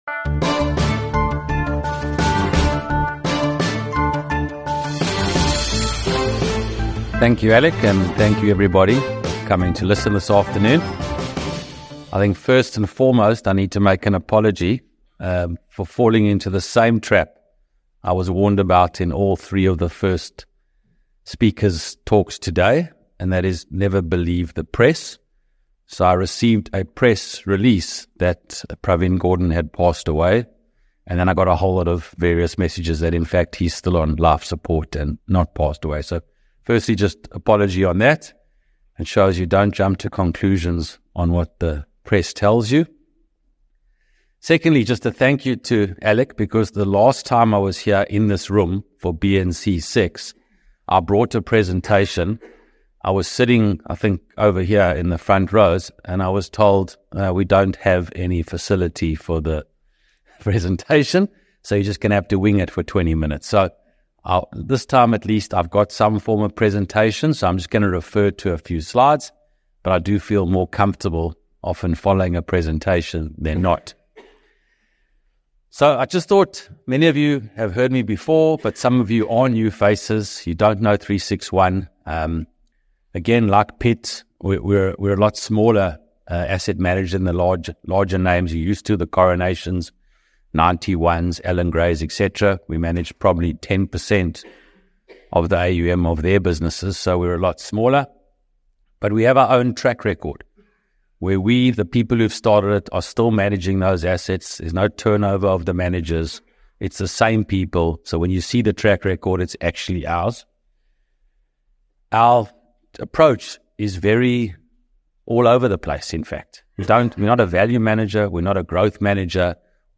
At the BizNews Investment Conference BNIC#1